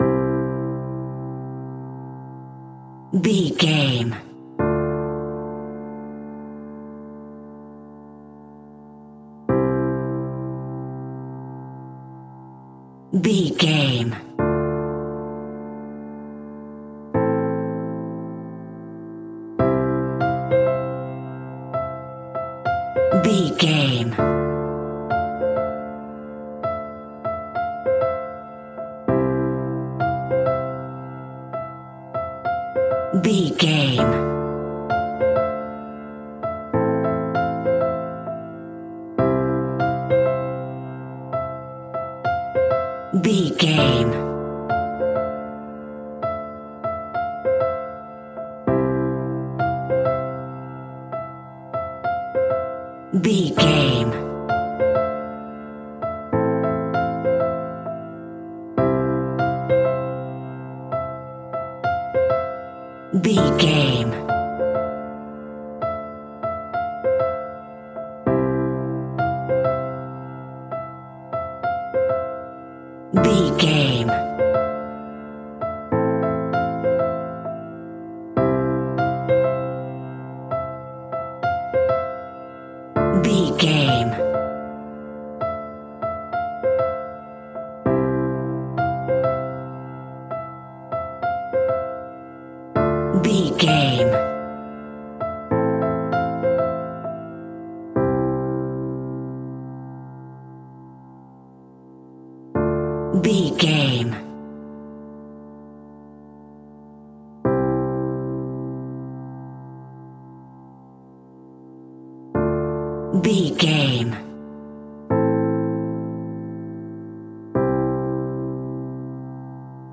Melodic and light piano music in a major key.
Regal and romantic, a classy piece of classical music.
Ionian/Major
soft